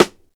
SNARE_WIN_THE_WAR.wav